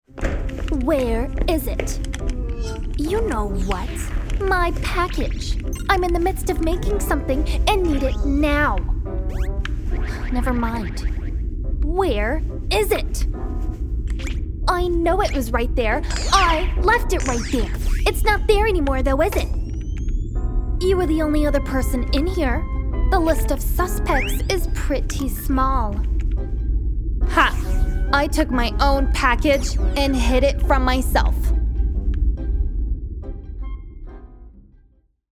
Animation (2) - EN